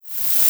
edm-perc-40.wav